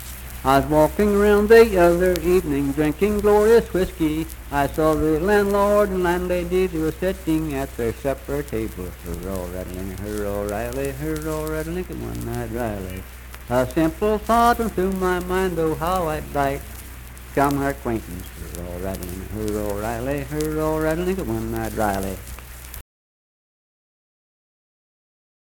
One Eyed Riley - West Virginia Folk Music | WVU Libraries
Unaccompanied vocal music performance
Verse-refrain 2(4-6w/R).
Voice (sung)